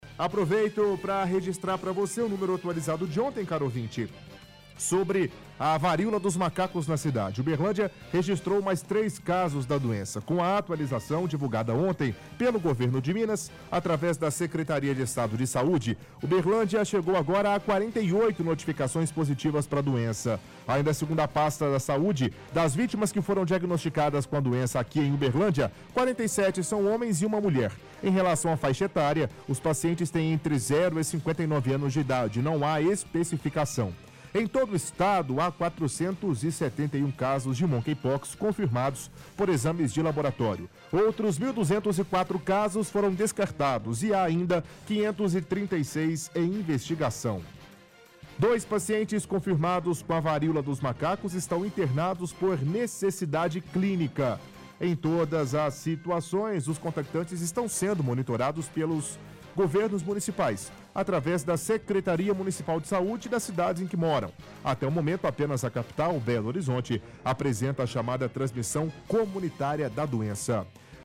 – Leitura de reportagem do Dário de Uberlândia sobre o último boletim estadual e atualização de casos no município.